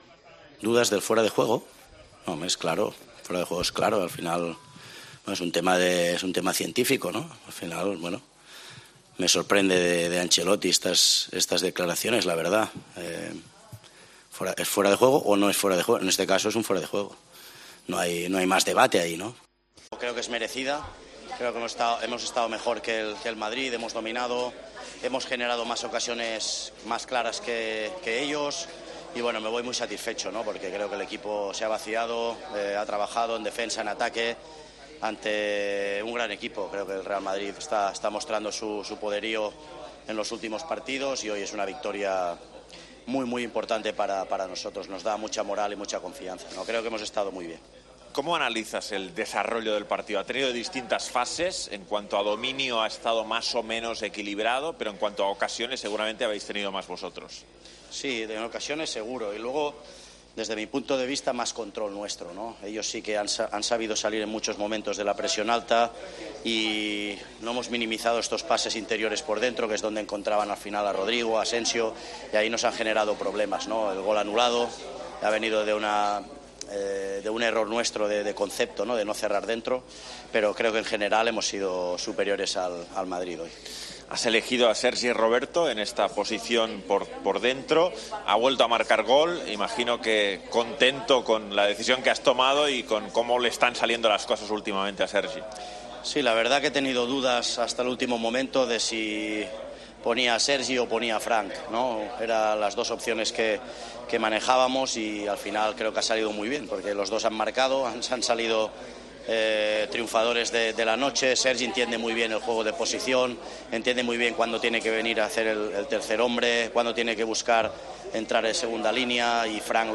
El entrenador del Barcelona habló en Movistar de la victoria ante el Madrid por 2-1, que deja a los azulgranas muy cerca del título de Liga.